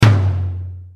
drum_tom_lo_hard.mp3